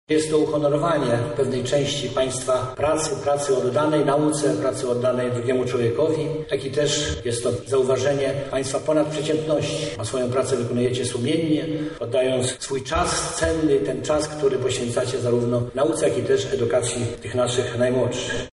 „Te odznaczenia podnoszą prestiż Uniwersytetu” – mówi wicewojewoda lubelski, Marian Starownik.